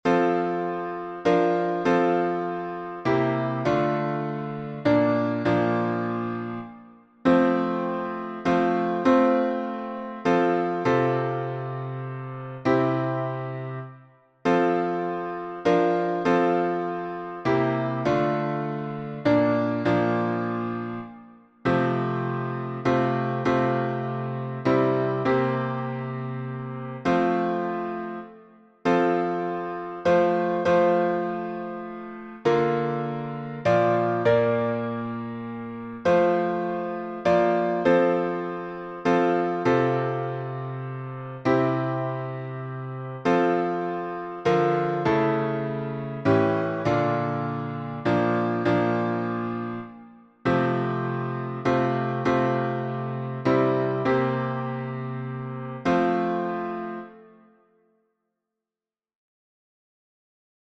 Near the Cross — in 6/8 with alternate notation and refrain.
Key signature: F major (1 flat) Time signature: 6/8
Near_The_Cross_sixEight_alt.mp3